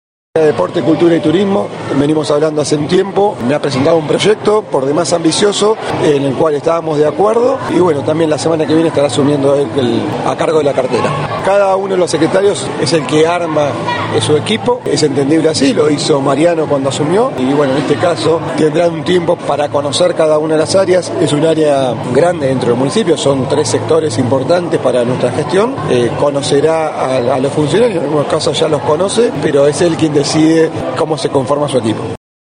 Luego de participar del acto por el 25 de Mayo que se realizó en la Escuela N° 24, el intendente Matías Taccetta formuló declaraciones a la prensa, confirmando que Juan Ripa se incorporaría al área de Obras Públicas y que Walter Torres estaría a cargo de las áreas de Turismo, Cultura y Deportes.